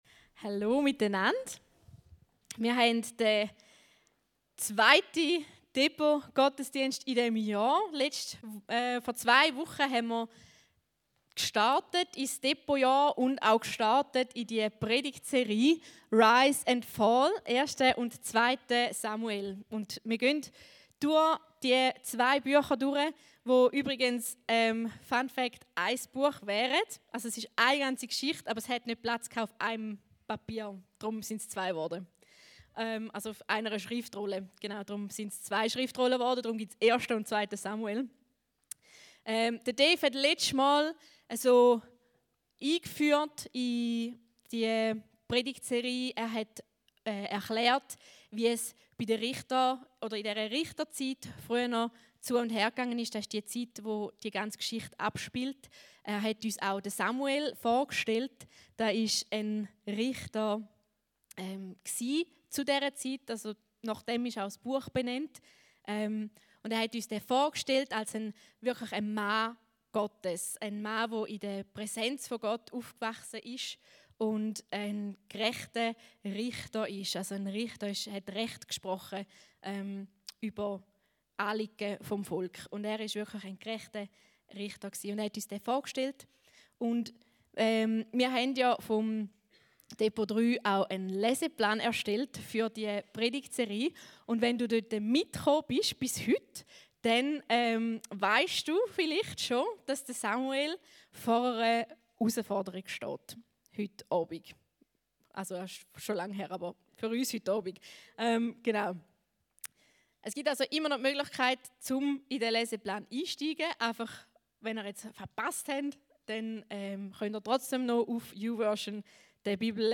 Gottesdienste
Genau mit diesem Bild startet die Predigt – und zeigt: Jesus stellt die Logik unserer Welt auf den Kopf.